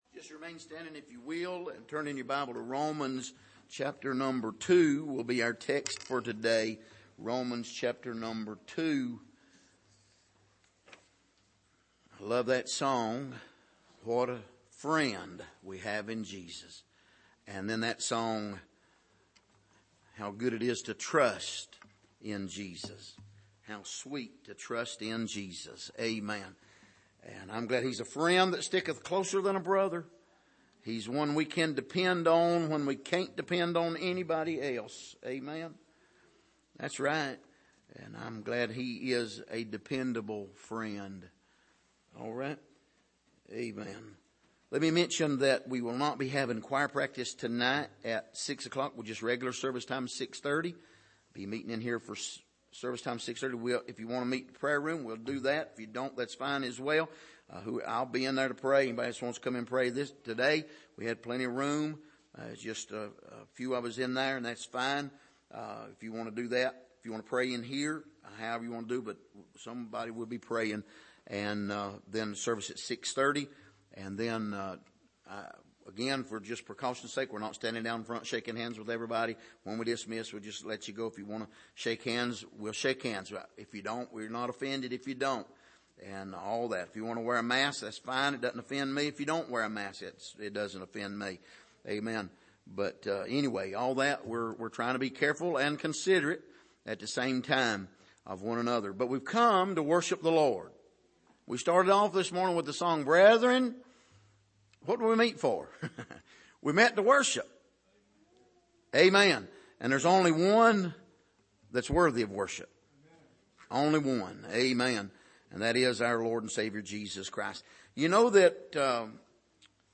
Passage: Romans 3:1-8 Service: Sunday Morning